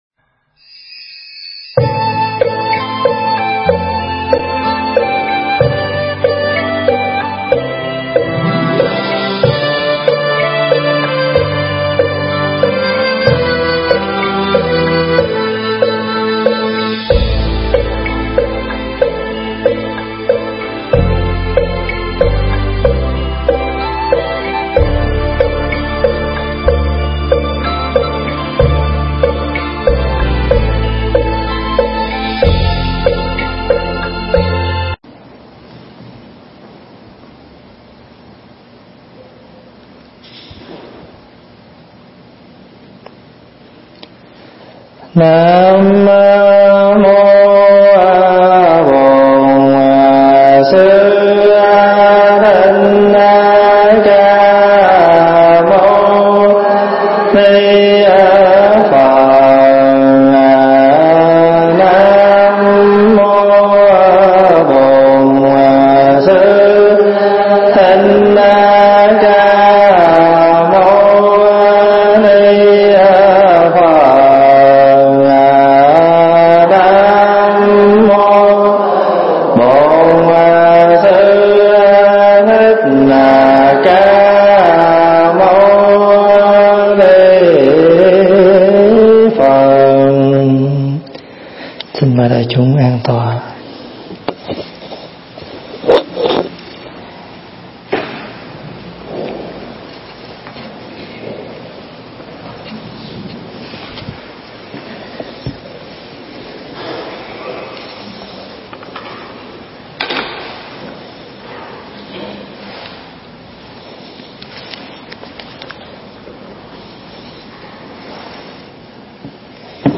thuyết giảng tại Tu Viện Trúc Lâm, Canada